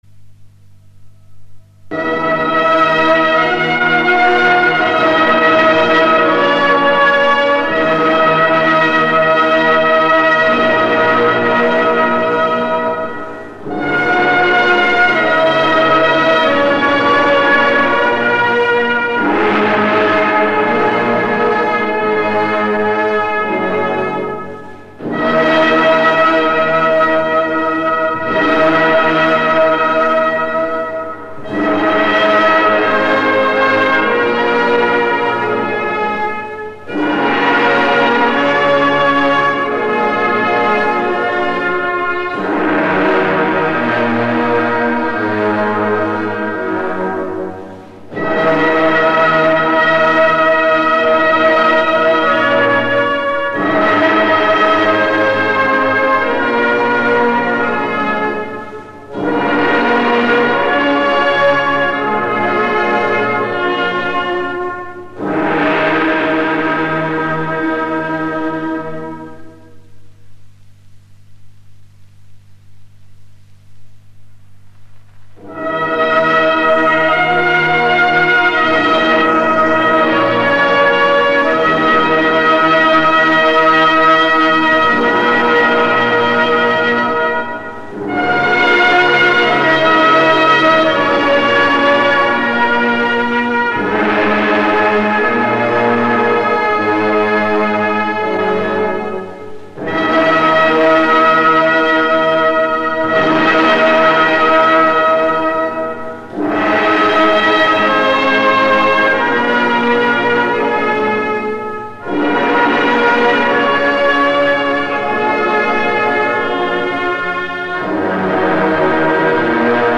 [25/2/2010] 【不喜勿入】中国人民解放军军乐团演奏的《哀乐》伤感
另附关于该曲的一些资料，载于网络 ：专门用于丧葬或追悼仪式的悲哀乐曲。
中国现用的哀乐选自陕北安塞地区的民乐。
演奏：中国人民解放军军乐团